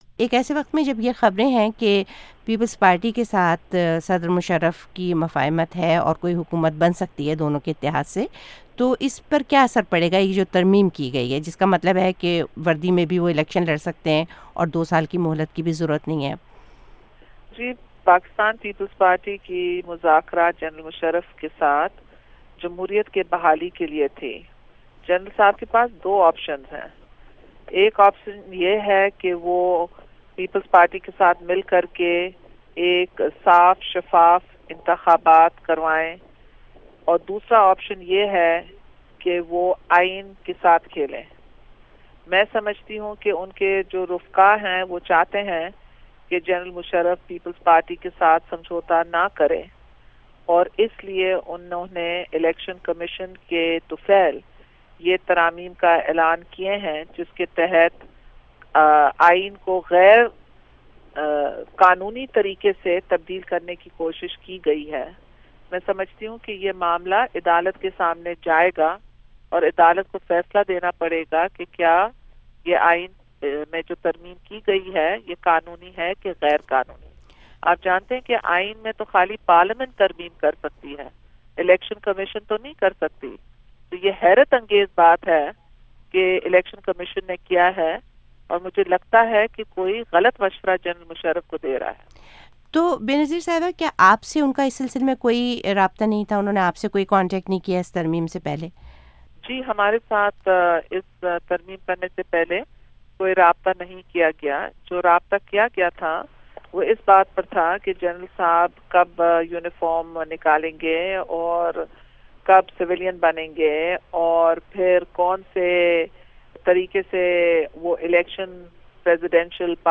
Benazir Bhutto Interview